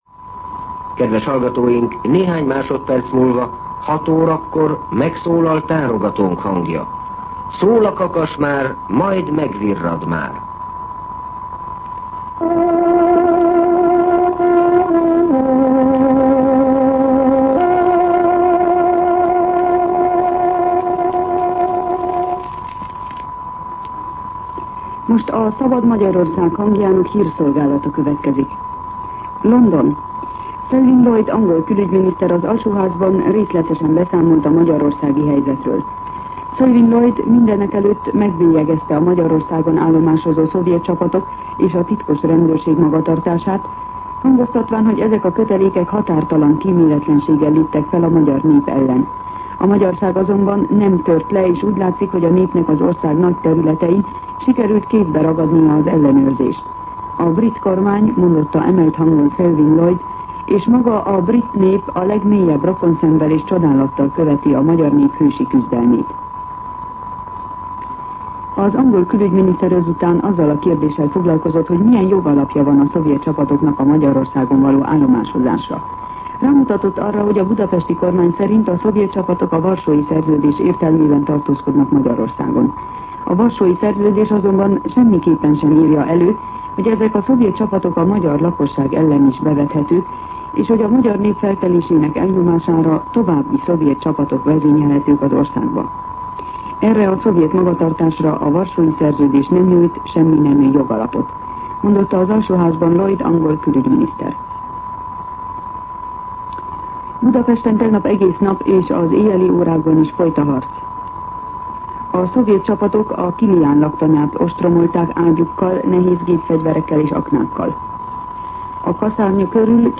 06:00 óra. Hírszolgálat